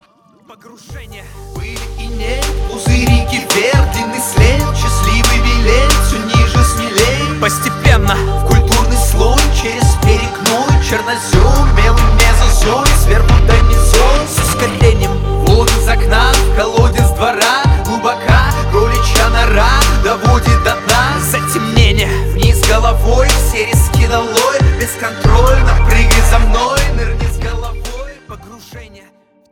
• Качество: 320, Stereo
мужской вокал
русский рэп
спокойные
качающие